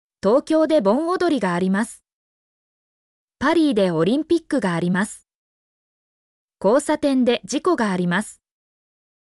mp3-output-ttsfreedotcom-9_gr7eLyji.mp3